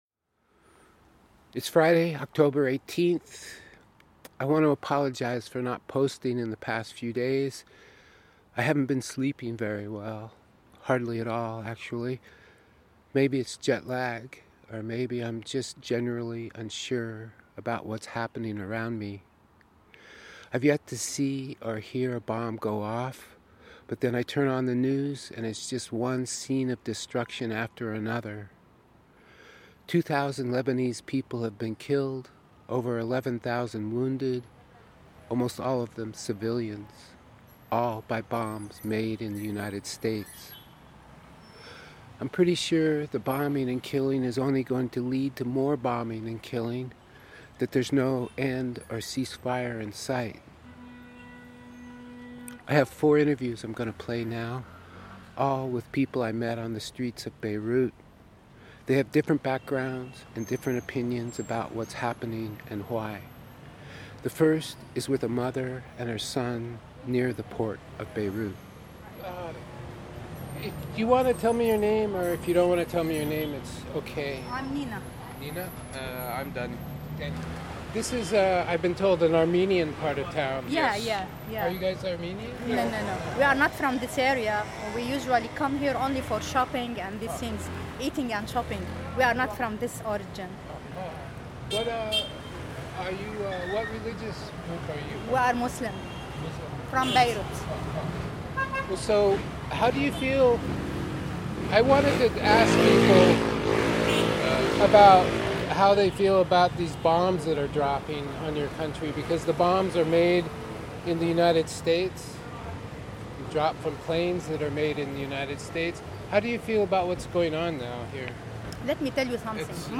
Four more interviews with people on the street in Beirut.